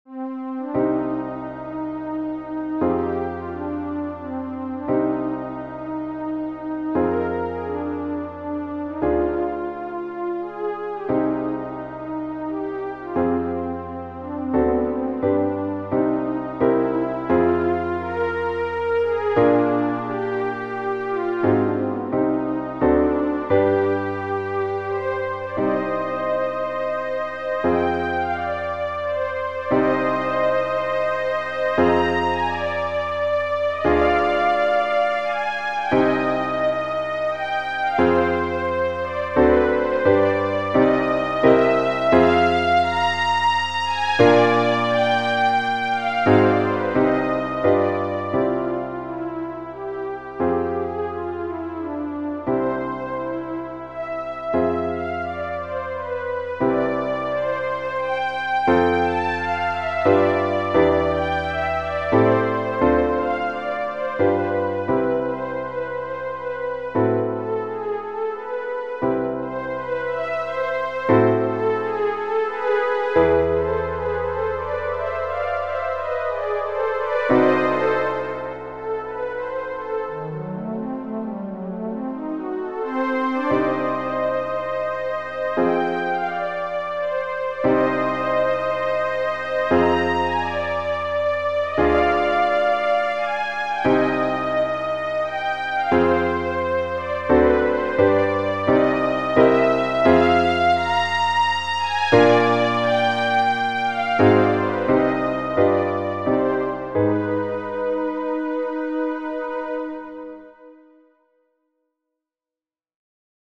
Brahms, J. Genere: Romantiche La Sinfonia n. 3 in fa maggiore per orchestra, op. 90 fu composta da Brahms nel 1883, quando era all'apice della sua fama e della sua maturità creativa.